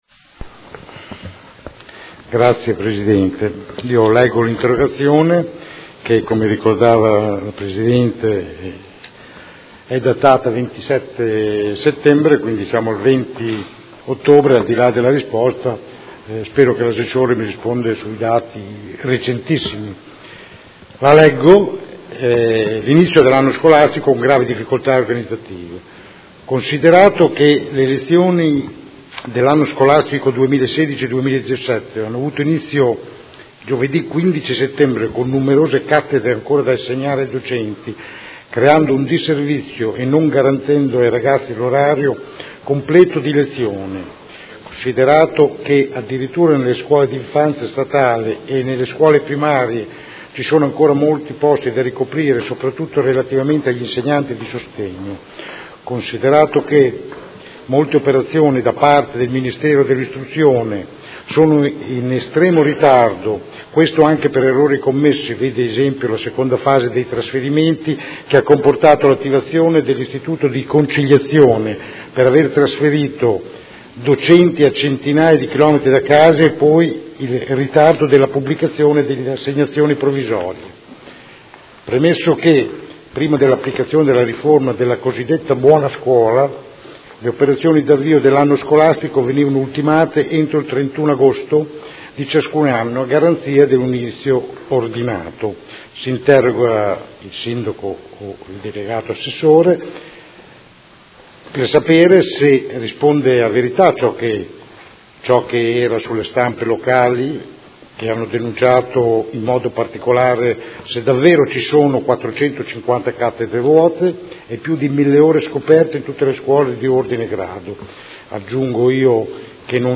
Seduta del 20/10/2016. Interrogazione del Consigliere Rocco (FaS-SI) avente per oggetto: Inizio anno scolastico con gravi difficoltà organizzative